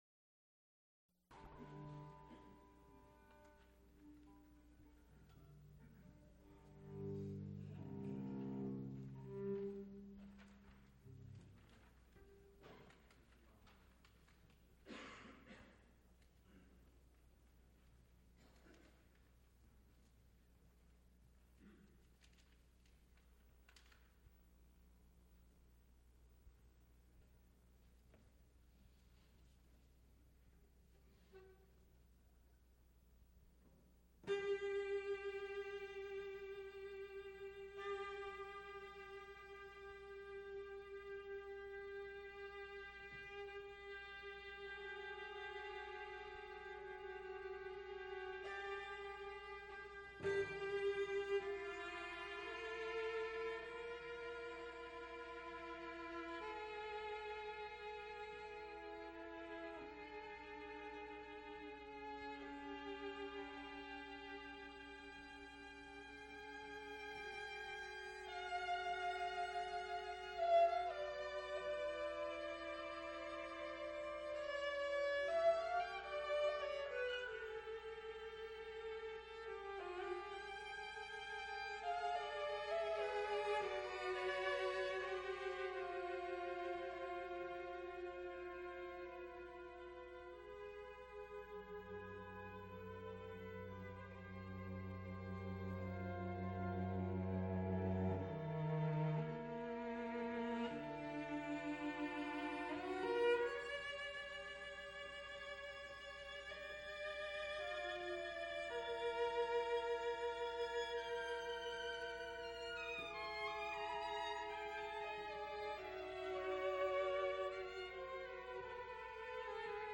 Recorded live March 6, 1975, Frick Fine Arts Auditoruium, University of Pittsburgh.
Trios, violin, viola, cello., Pittsburgh concert series (University of Pittsburgh).
Music--20th century String trios